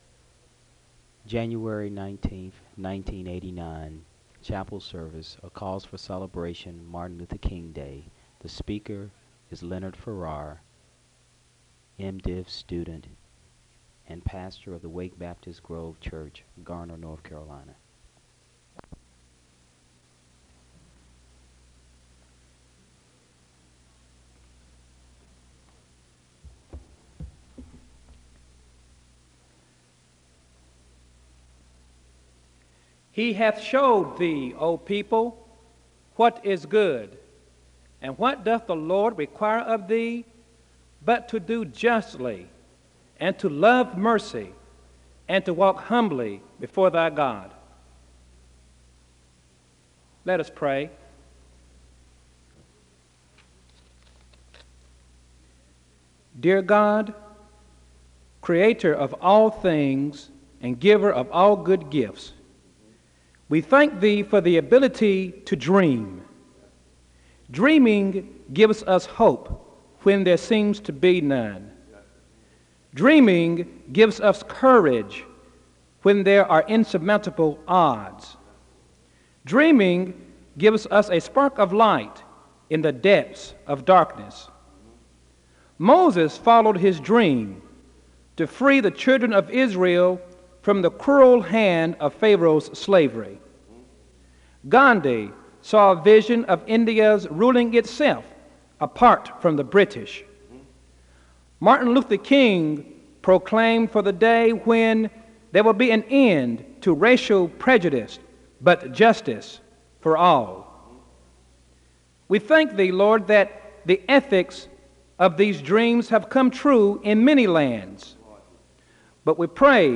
A word of prayer is given (0:19-2:50). A woman gives a testimony of personal trials (2:51-5:42).
A hymn ends the service (29:28-31:50).
SEBTS Chapel and Special Event Recordings SEBTS Chapel and Special Event Recordings